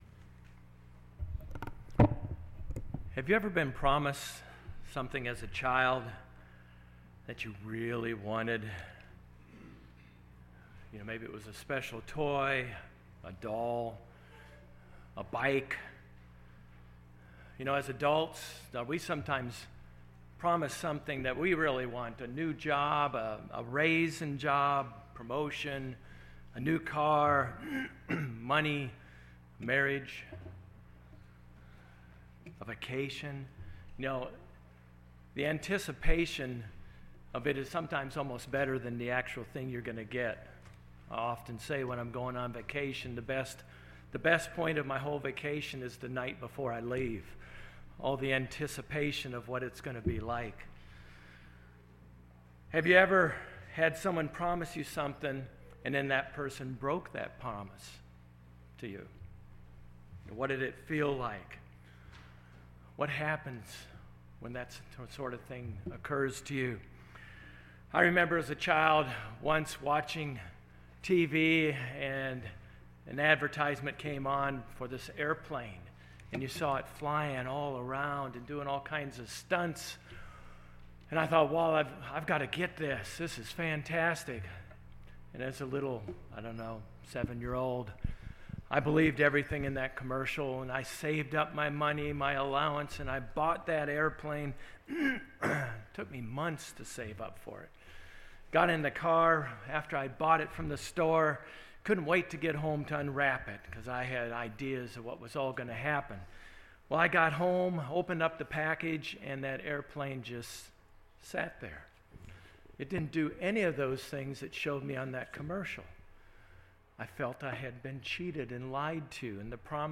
Sermons
Given in Jacksonville, FL